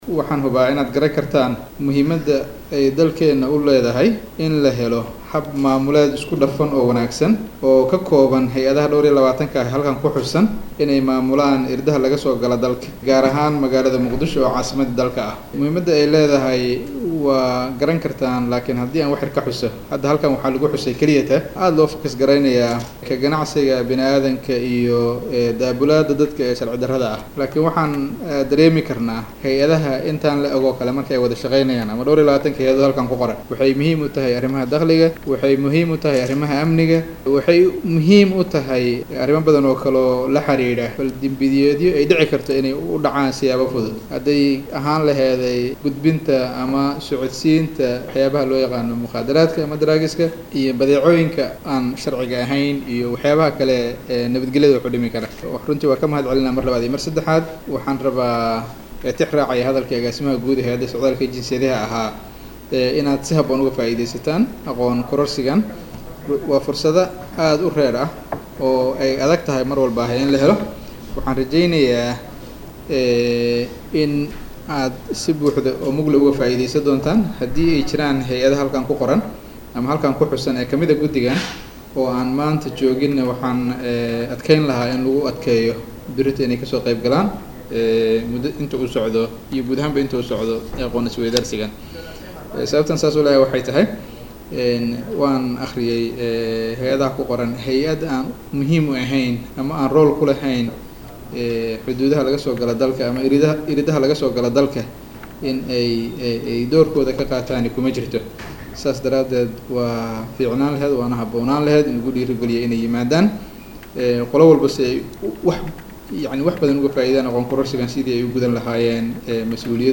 Hadalkaan ayaa Wasiirka Wasaaradda Amniga Soomaaliya wuxuu ka sheegay xilli Maanta Muqdisho uu ka furay tababar ku saabsan ka hortagga dambiyada socdaalka.